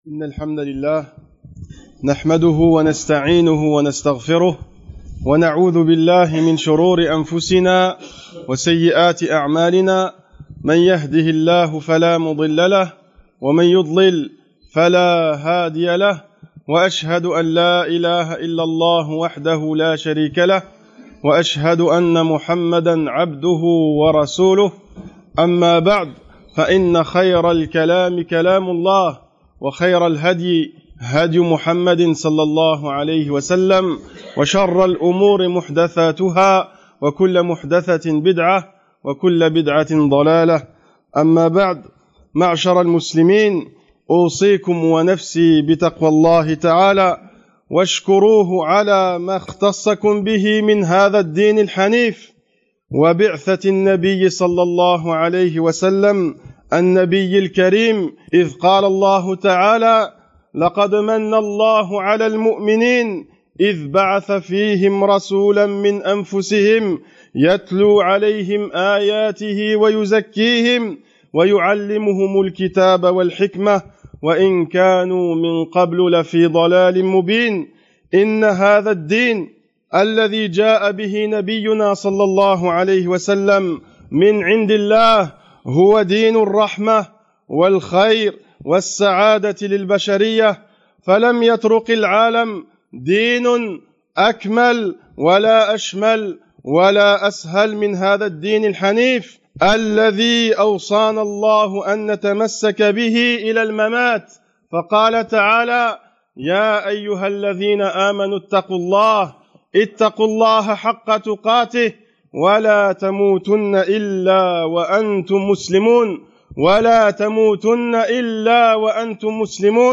Ce sermon expose les preuves du coran et de la sunna concernant l’indulgence de la religion islami